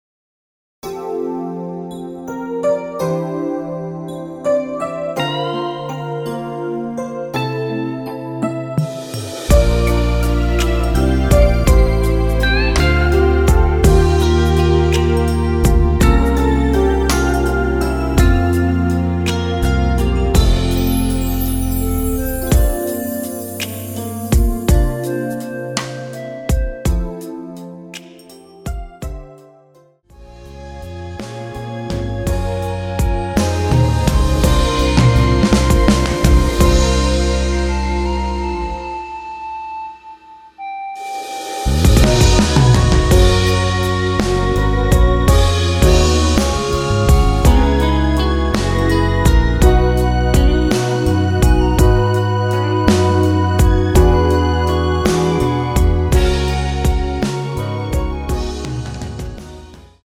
원키에서(+3)올린 멜로디 포함된 MR입니다.
앞부분30초, 뒷부분30초씩 편집해서 올려 드리고 있습니다.
중간에 음이 끈어지고 다시 나오는 이유는